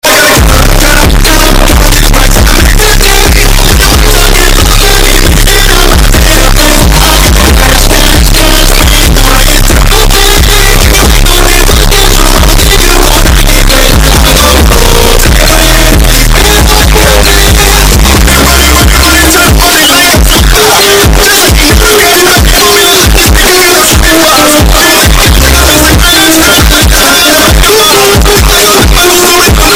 Beanos Earrape